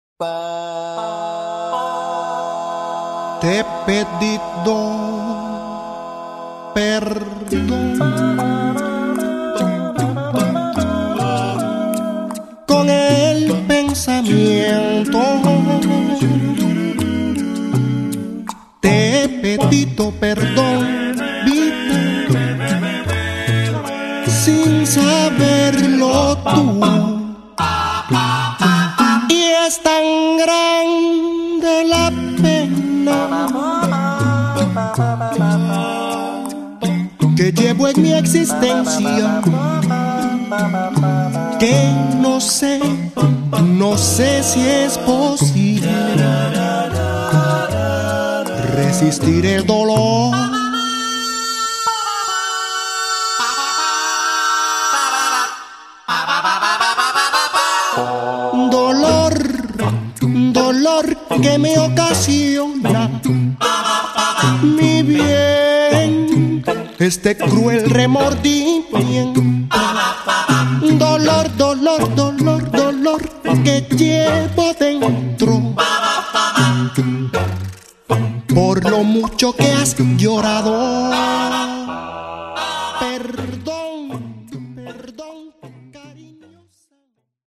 Cubani.
Le percussioni infatti sono sbalorditive.